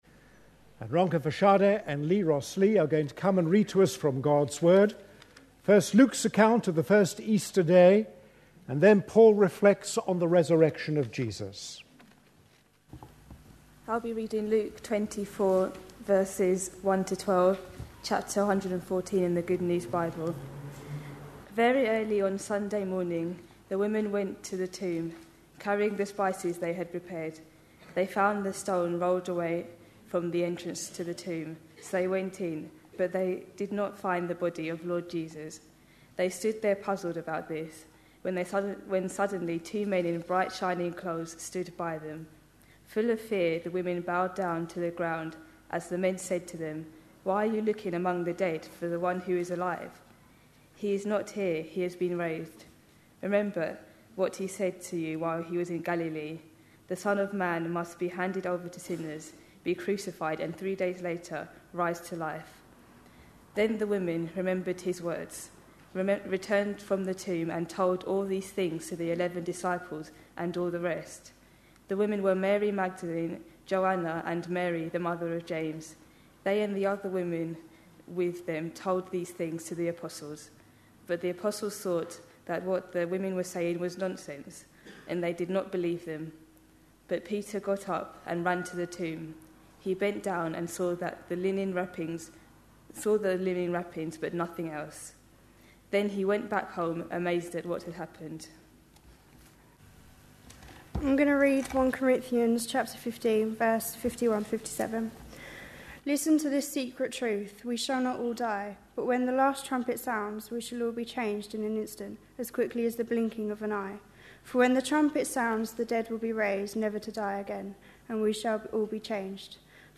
A sermon preached on 8th April, 2012.
The service was held on Easter Sunday.